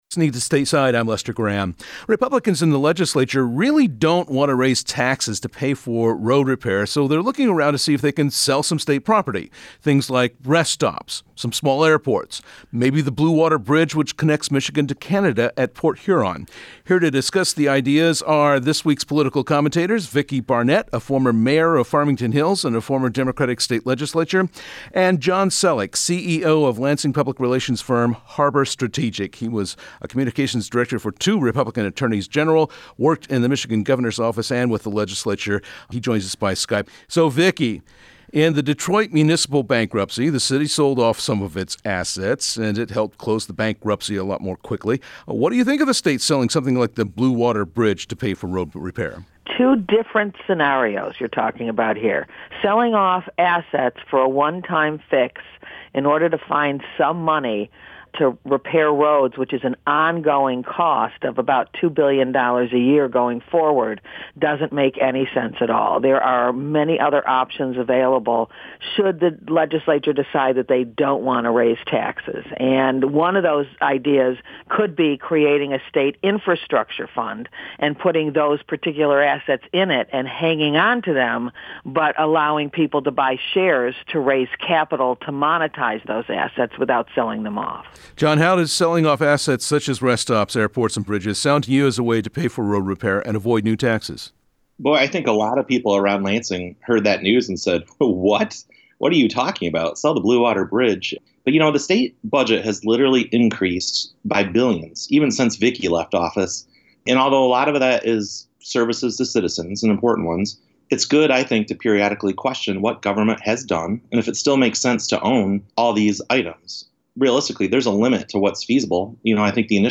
Stateside's conversation